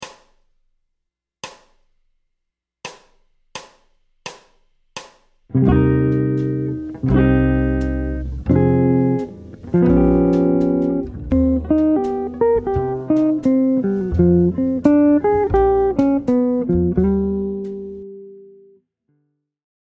Les arpèges du relatif mineur de la progression initiale font remarquablement bien le job et apporte la petite touche d’originalité du trait